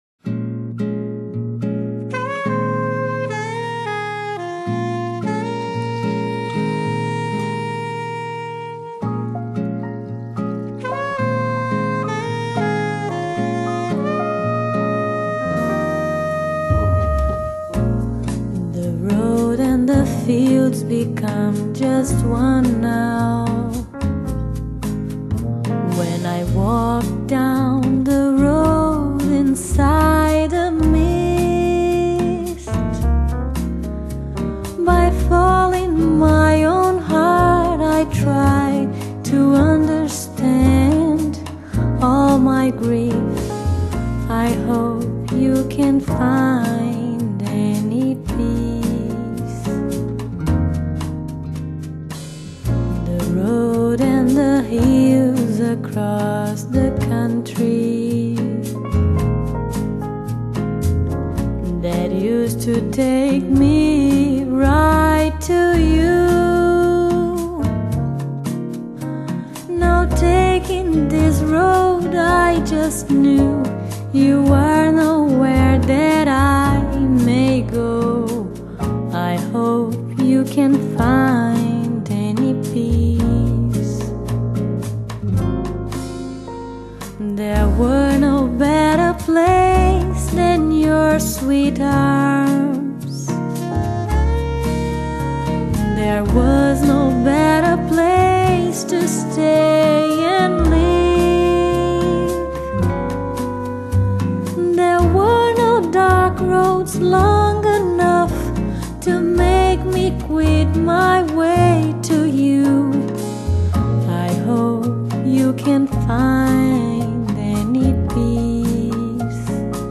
是首批中國歌曲以BOSSA NOVA藍調的風格走向世界，極具珍藏價值的發燒精品。